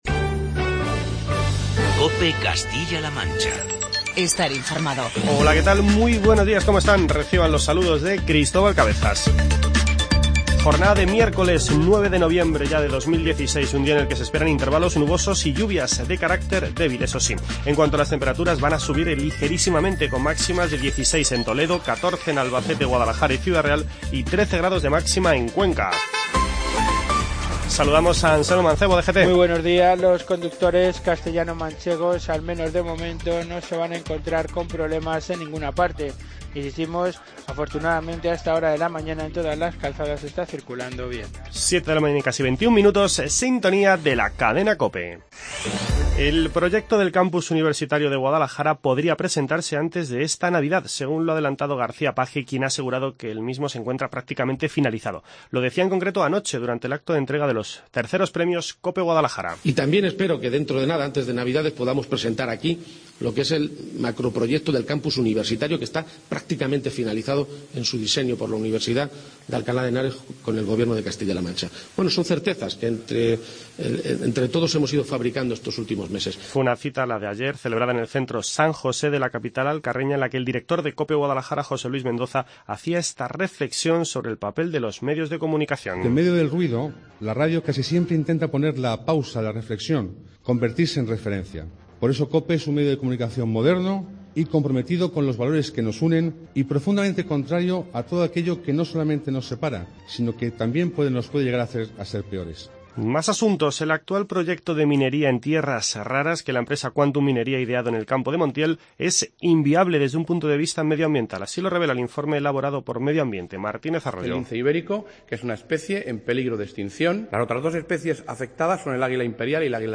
El proyecto del campus universitario de Guadalajara que el Gobierno regional está diseñando en colaboración con la Universidad de Alcalá (UAH) podría presentarse antes de esta Navidad, según adelantó anoche el presidente de Castilla-La Mancha, Emiliano García-Page. Durante el acto de entrega de los III Premios COPE Guadalajara, el presidente de la Junta aseguró que el proyecto está "prácticamente finalizado".